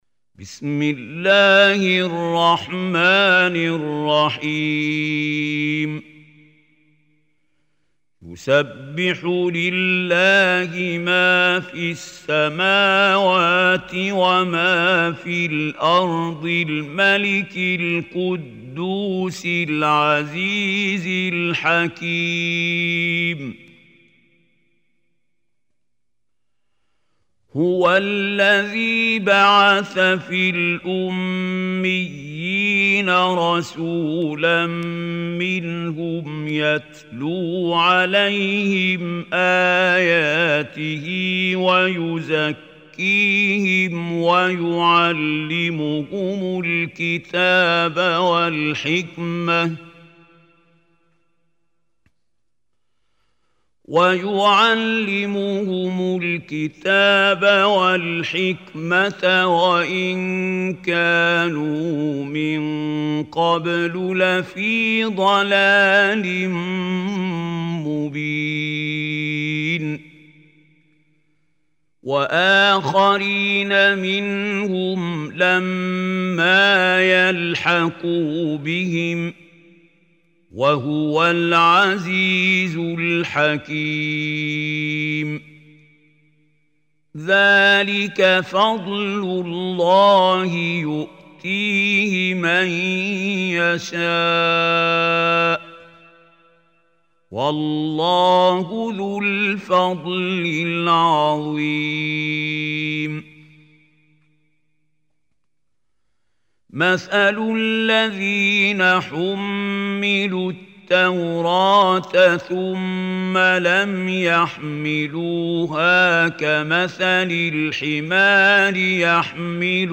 Surah Jumah Recitation by Mahmoud Khalil Hussary
Surah Jumah is 62 chapter of Holy Quran. Listen or play online mp3 tilawat / recitation in Arabic in the beautiful voice of Sheikh Mahmoud Khalil Al Hussary.